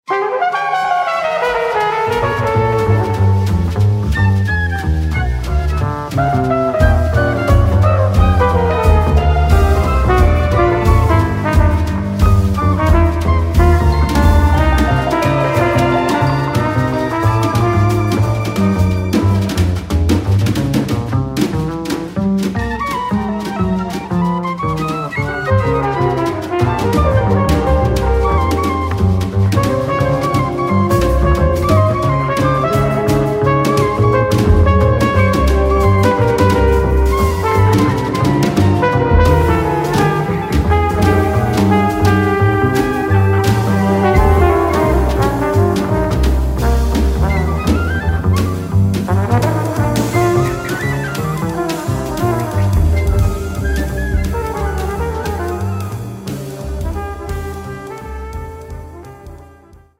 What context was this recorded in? This album was recorded live